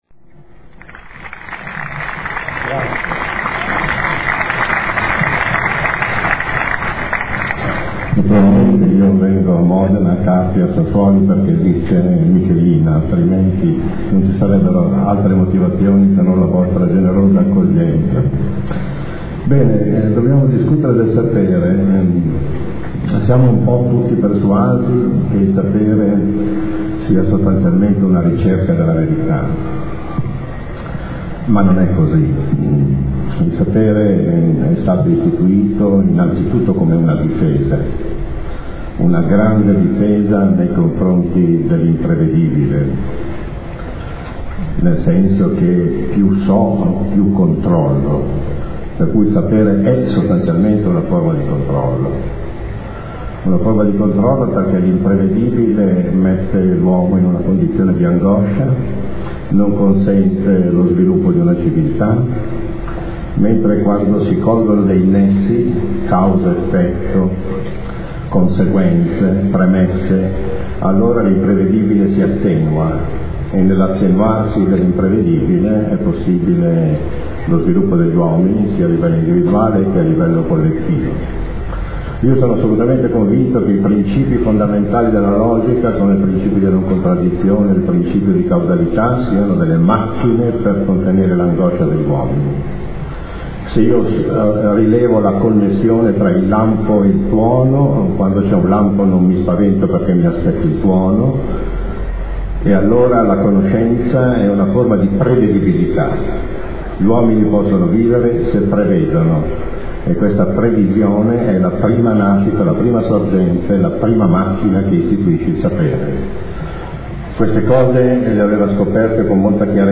Anno 2007 - Carpi